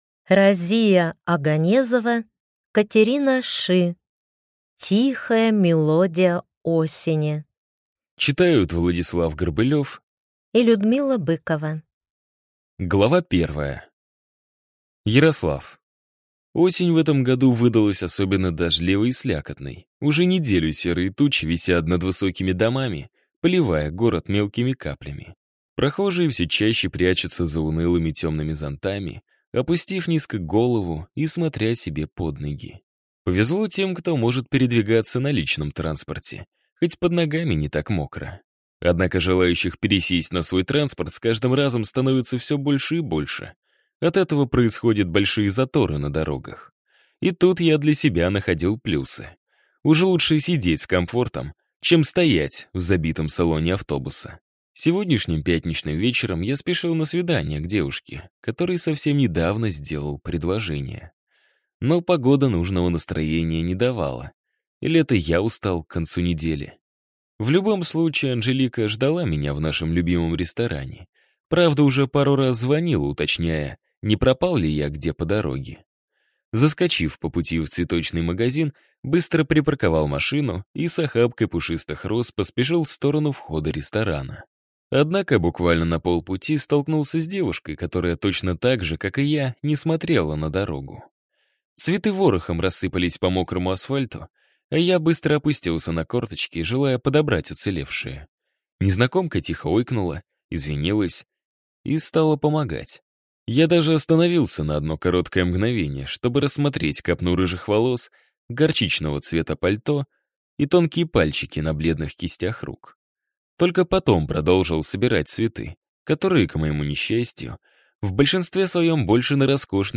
Аудиокнига Тихая мелодия осени | Библиотека аудиокниг
Прослушать и бесплатно скачать фрагмент аудиокниги